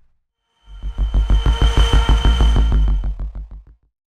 podpassby02.wav